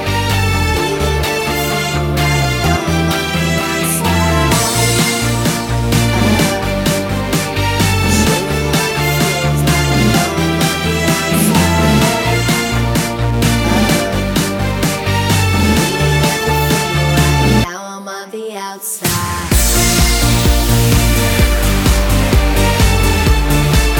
no Backing Vocals Dance 3:46 Buy £1.50